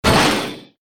KART_Hitting_Metal_Fence.ogg